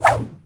FootSwing4.wav